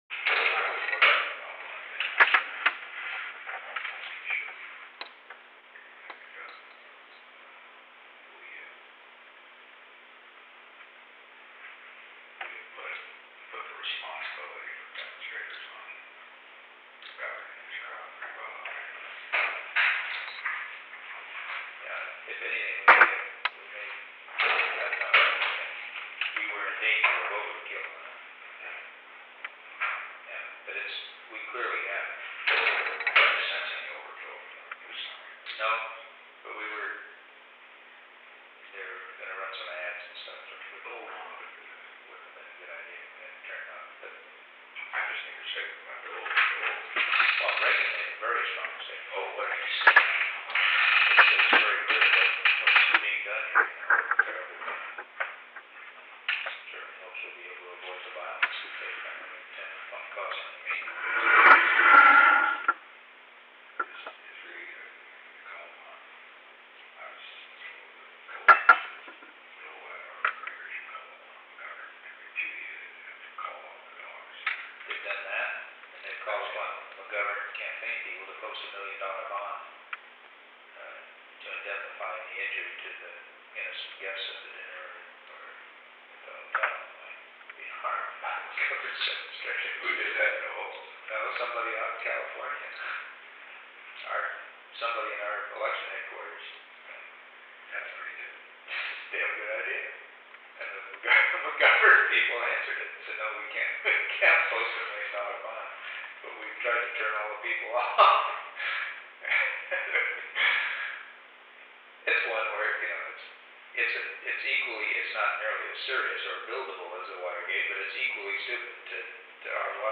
Location: Oval Office The President met with H.R. (“Bob”) Haldeman.
Secret White House Tapes